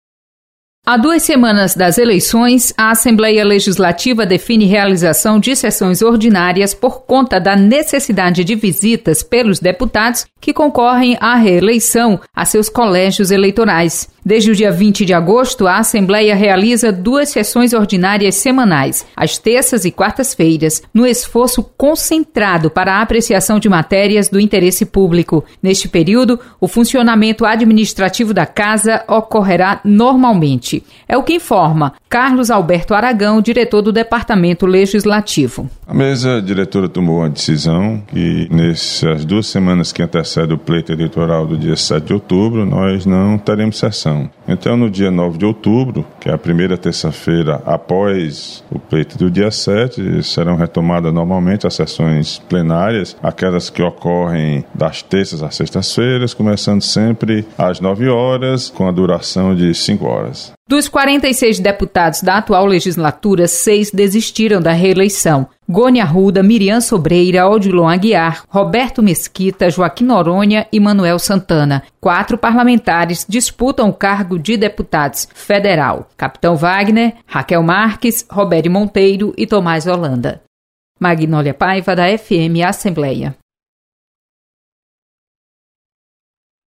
Mesa Diretora define período de recesso branco na Assembleia Legislativa. Repórter